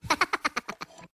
Звуки миньонов
Смех миньона при получении SMS